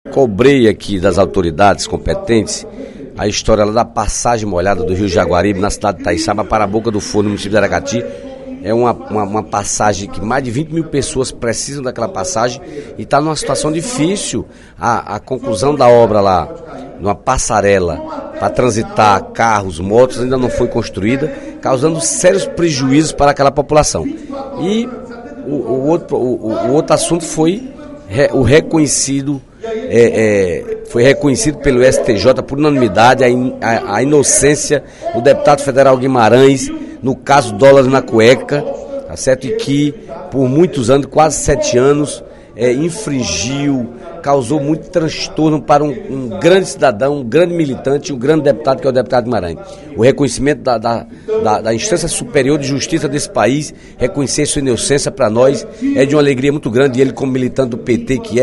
O deputado Dedé Teixeira (PT) destacou, durante pronunciamento na sessão plenária desta terça-feira (03/07), que o Superior Tribunal de Justiça (STJ) livrou o vice-líder do Governo Dilma, deputado José Guimarães (PT-CE) da ação de improbidade administrativaque que ele figurava como réu.